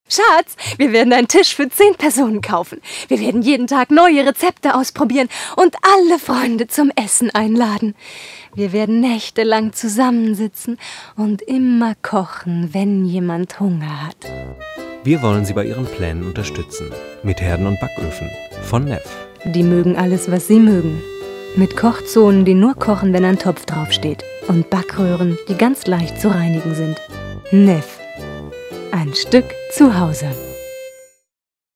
Sprechprobe: Sonstiges (Muttersprache):
german female voice over artist